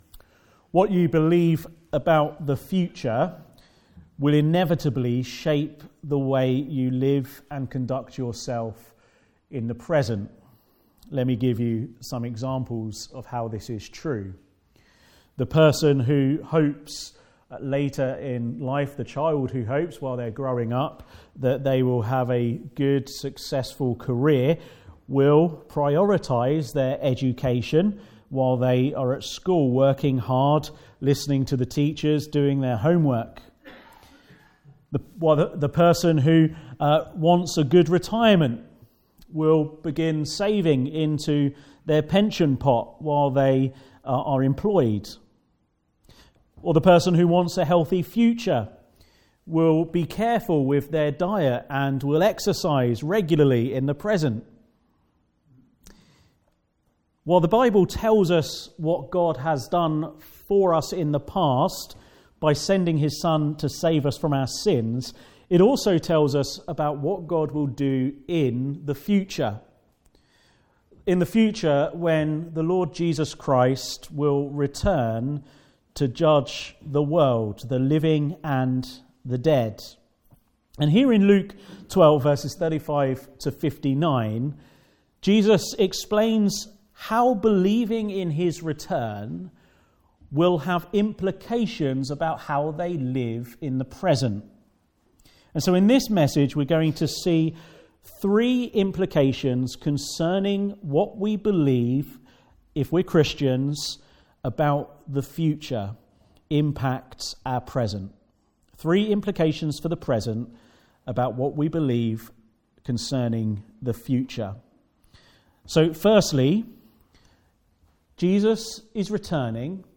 Guest Speaker
Passage: Isaiah 2: 1-11 Service Type: Afternoon Service